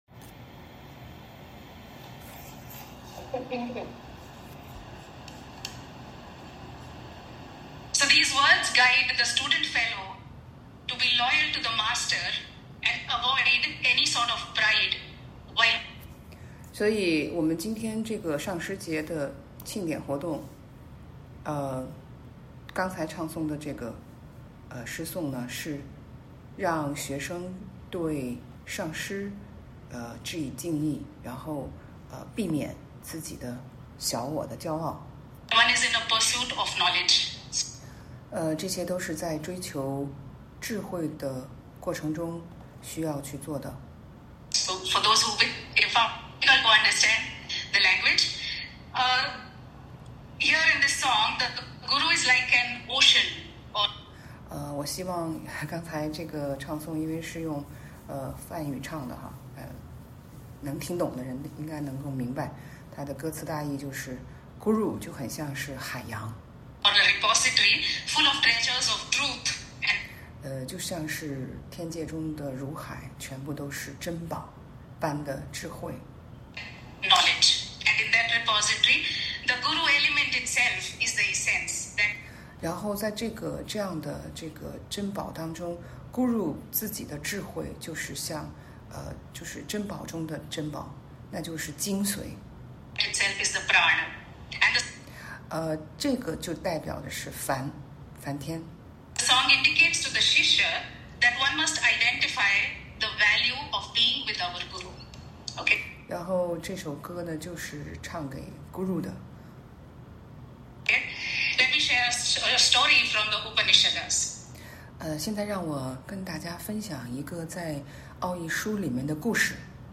以翻譯版的音訊形式